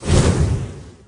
frank_swing_01.ogg